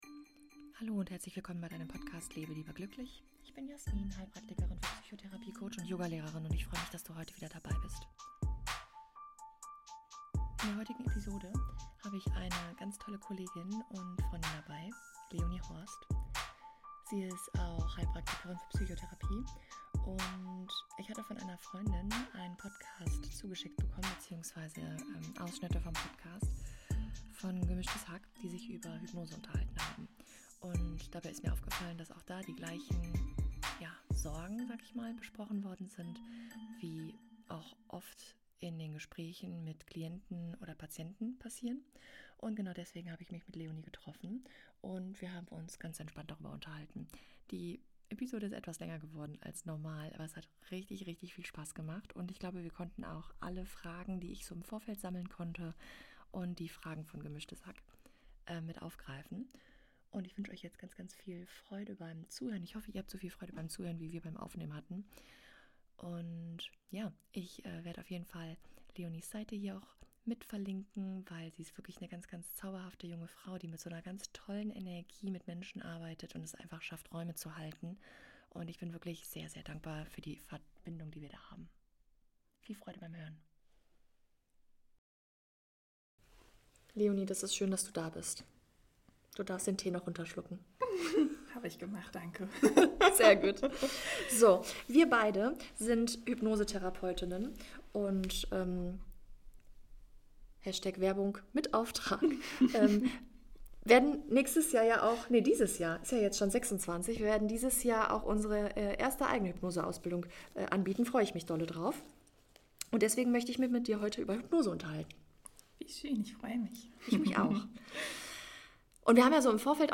Ein Gespräch über Vertrauen, innere Sicherheit und darüber, wie Veränderung entstehen kann, wenn wir dem Unterbewusstsein Raum geben.